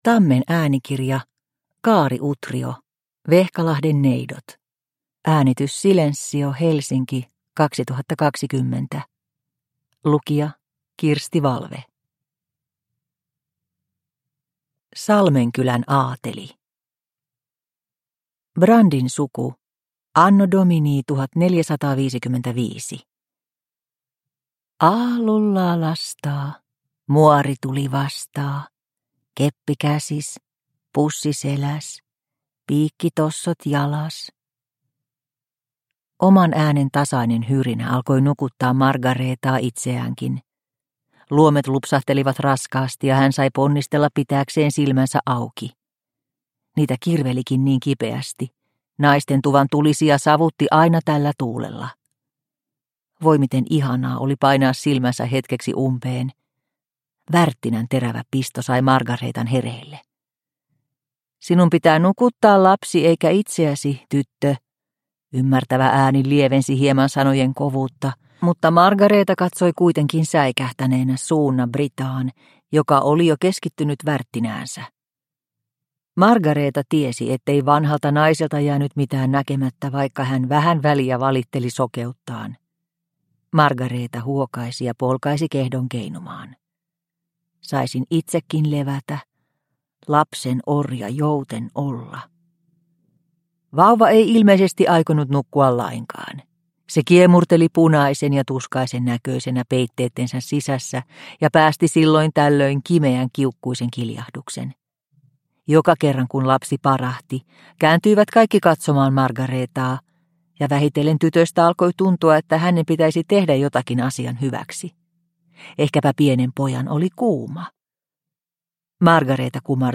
Vehkalahden neidot (ljudbok) av Kaari Utrio